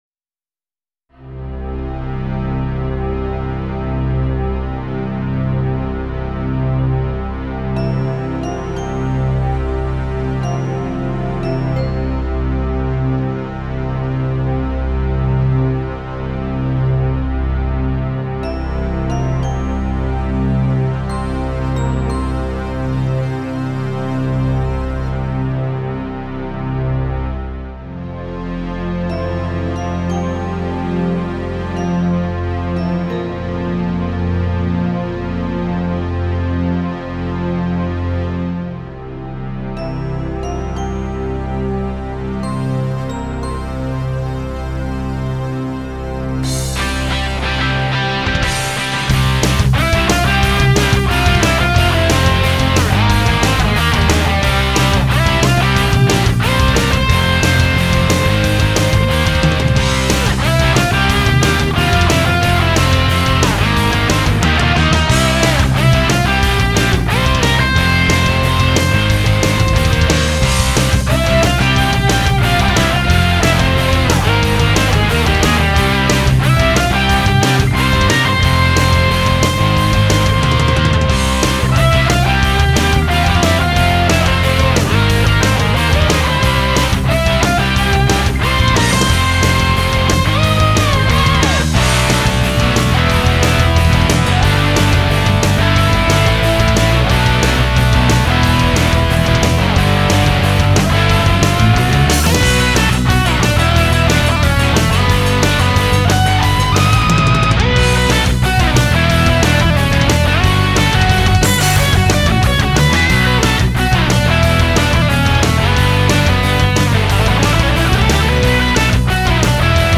Instrumental, Rock
This is the first song I ever did using computer based harddisk-recording.
Still, I think it’s not so bad for a first try, although I had heavy sync problems with my TerraTec EWS64XL 🙁 which you can unfortunately hear sometimes.
I got the inspiration to this song from watching TV series such as Melrose Place or Beverly Hills. Although it turned out quite heavier and a little different from the intro songs of those series I think it still captures the spirit and has an easy to remember hook line.